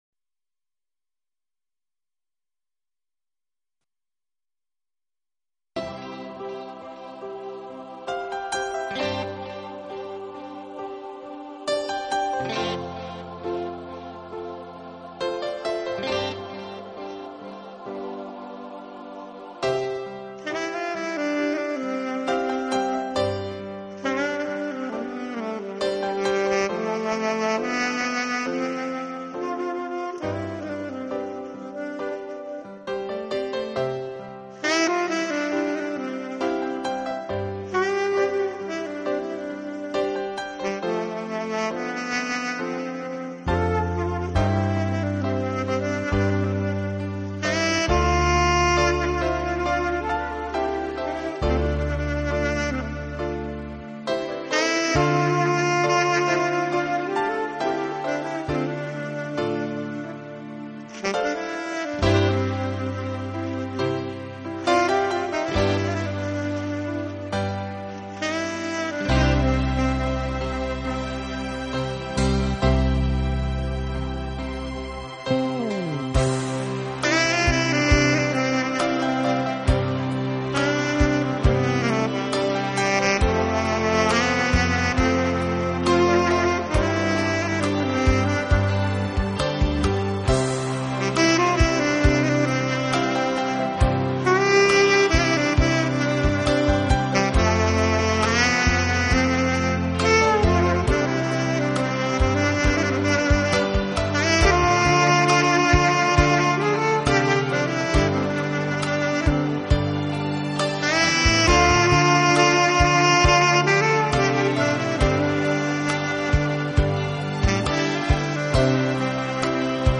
悠悠的萨克斯，吹响的是那淡淡的感伤，浓浓的思绪；吹出的是你的往昔，你的等待，
你的情怀，恰如心在吟唱，沏上一杯浓香的咖啡，耳边飘起怀旧与感性的萨克斯。